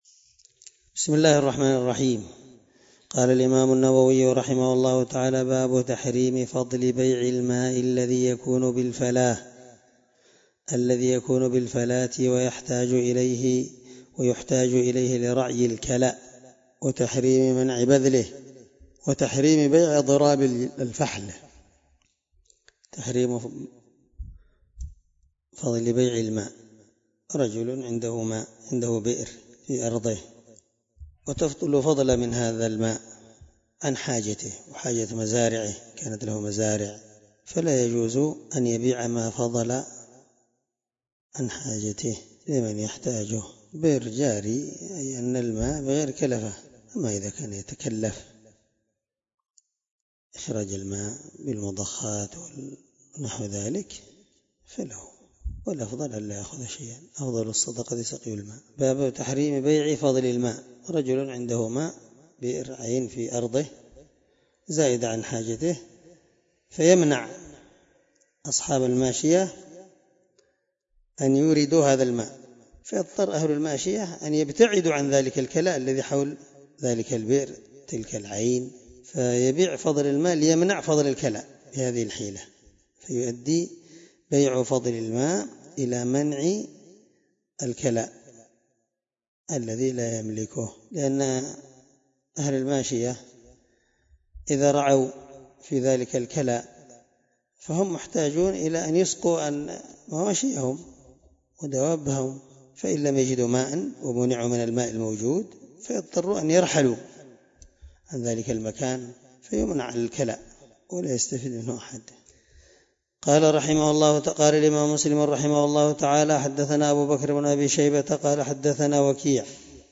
الدرس9من شرح كتاب المساقاة حديث رقم(1565-1566) من صحيح مسلم